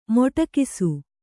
♪ moṭakisu